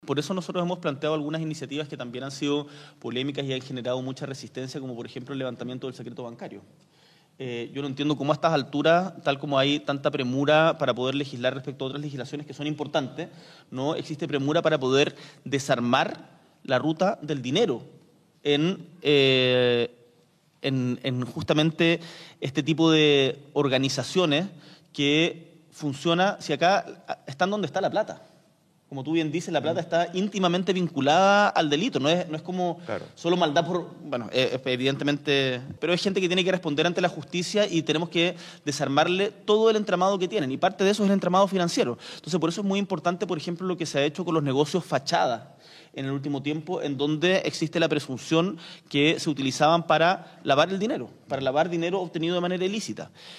Este jueves la Asociación de Radiofusores de Chile, Archi, realizó una cadena nacional con una entrevista al presidente Gabriel Boric Font, directamente desde el Palacio de La Moneda, en el marco del Día de la Libertad de Prensa.